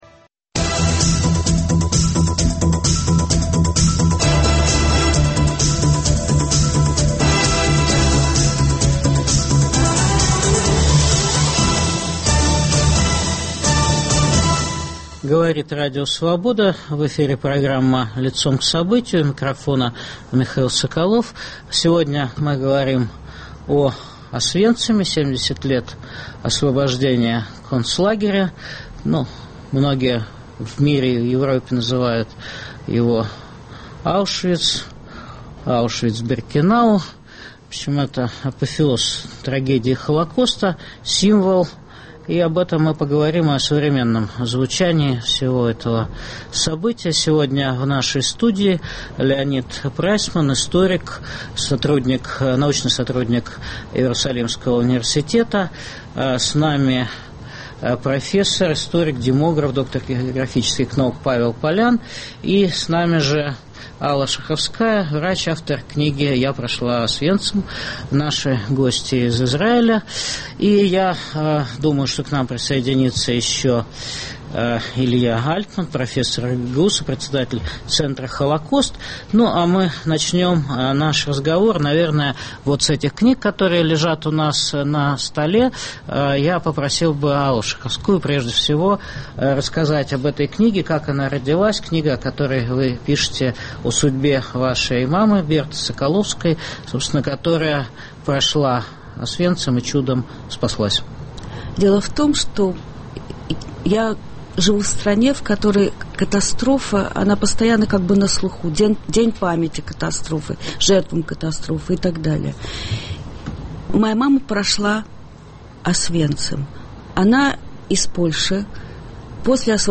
70-лет со дня освобождения нацистского лагеря смерти Аушвиц-Биркенау. В студии историки